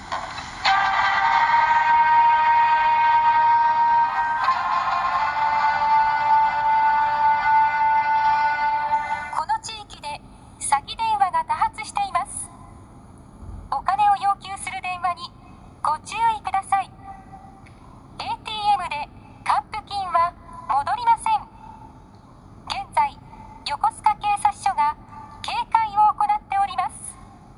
詐欺防止広報用音声サンプル
横須賀警察署では、特殊詐欺被害抑止のため不審電話を受けた地域で ベートーベン「運命 交響曲」冒頭 の 「ジャジャジャジャーン♪」 という音声とともにパトカーなどで警戒のため広報をしています。
広報用音声サンプルは こちら 広報用音声サンプル 再生する際は音量にご注意ください。